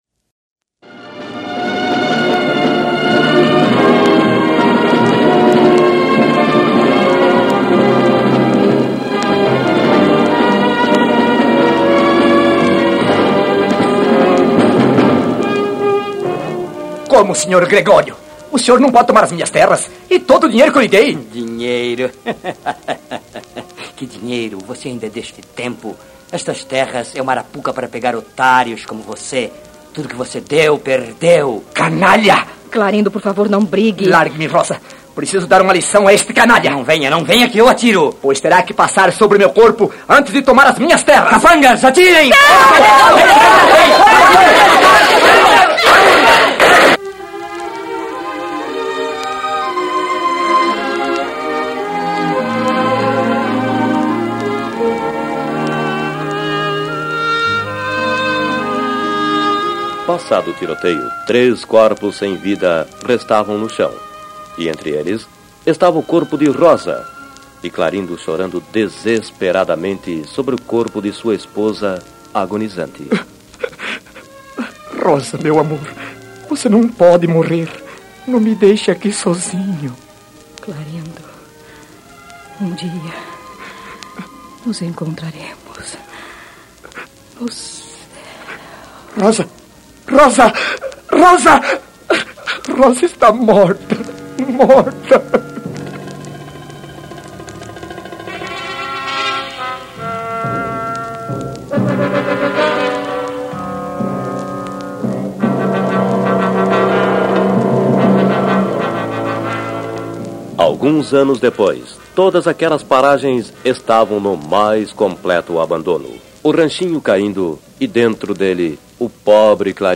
OS VALENTES TAMBÉM AMAM (“SOLIDÃO” ou “TERRA BANHADA DE SANGUE”) Melodrama rural em 04 atos Escrito em 1954